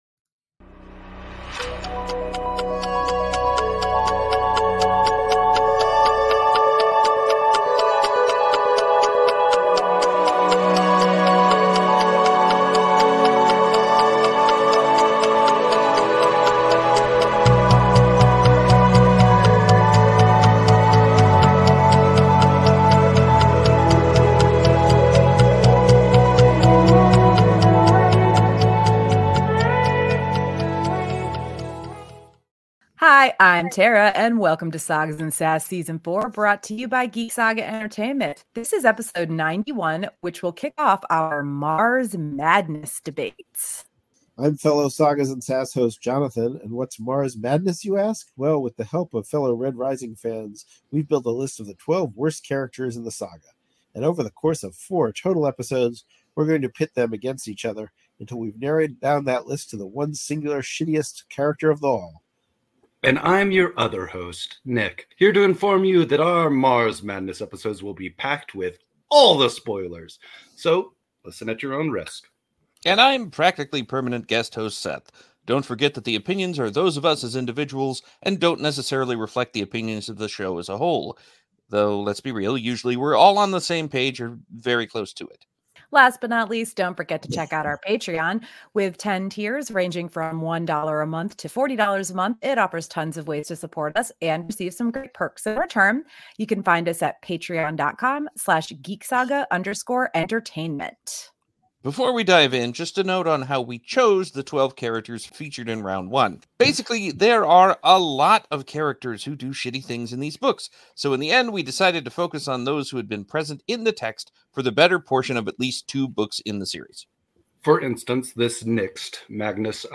Apologies for the sound issues in this episode – there were some connectivity problems that we didn’t realize were happening while recording 🙁